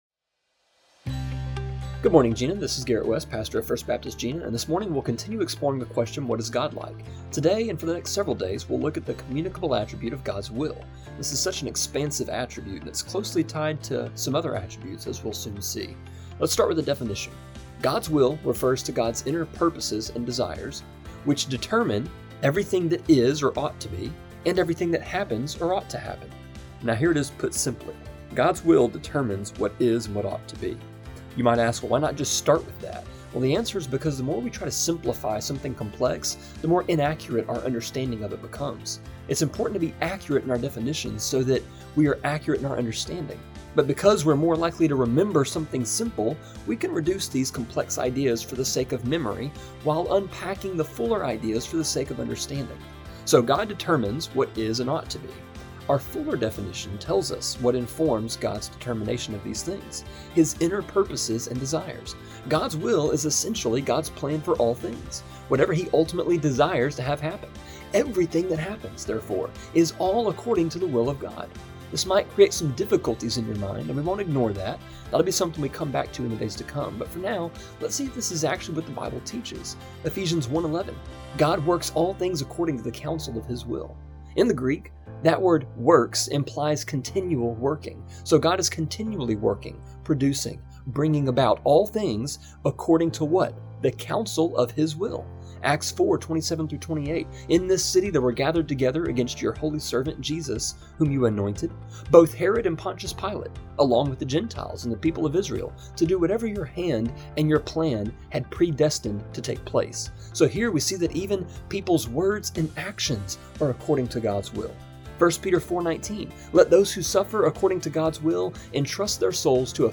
A three minute (-ish) devotion that airs Monday through Friday on KJNA just after 7am.